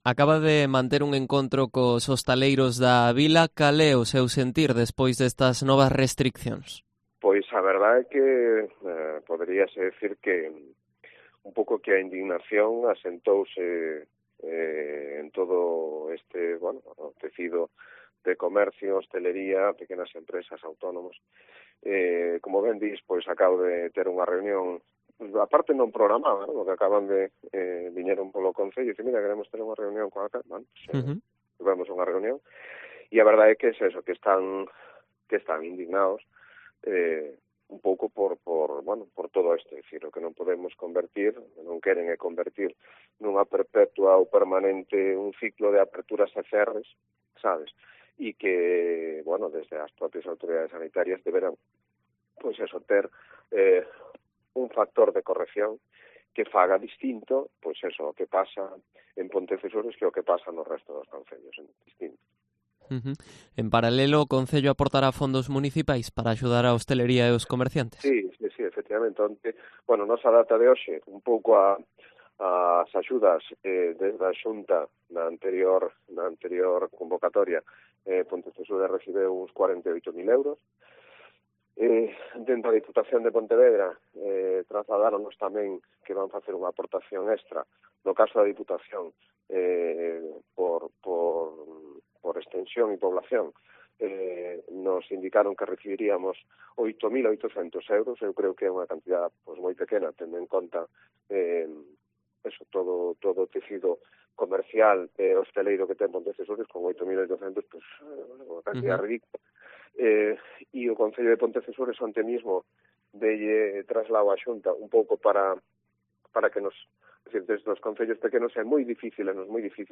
Entrevista a Vidal Seage, alcalde de Pontecesures